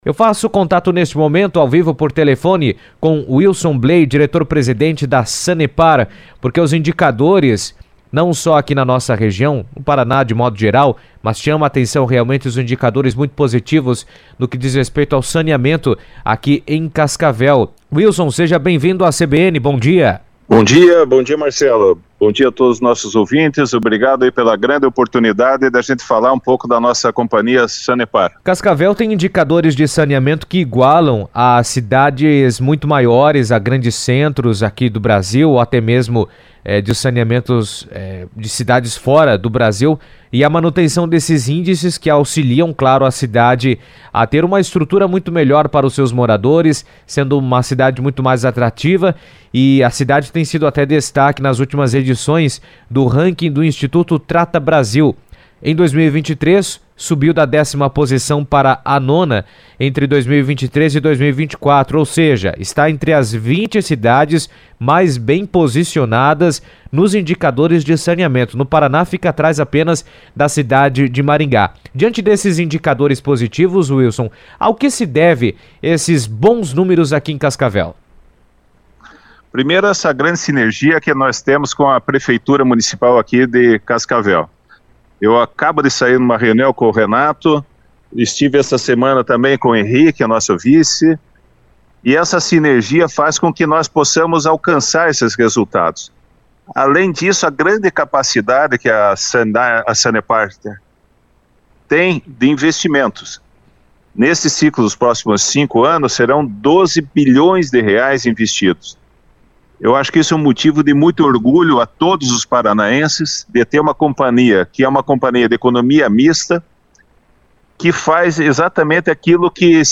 participou do CBN Cascavel por telefone e explicou sobre os investimentos realizados e os avanços que foram possíveis.